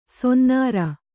♪ sonnāra